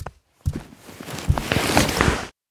胖子靠近.ogg